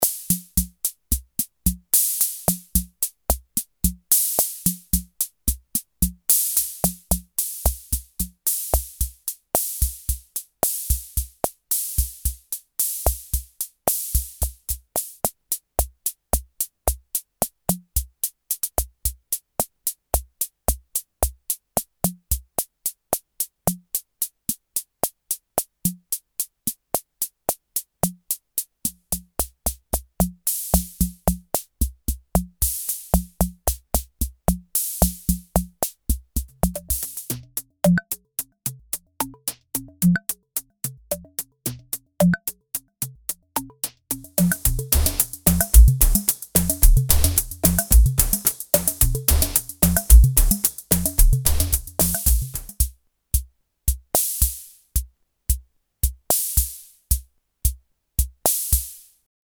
Sample Pack советской драм машины Эстрадин 11, soviet drum machine Estradin 11 96 kHz 32 bit float
A sampler library recorded in collector quality 96 kHz 32 bit float from a rare Soviet drum machine Estradin 11. Contains 14 built-in presets, 3 user and 5 processed effects, as well as noises and artifacts of the original 1981 release.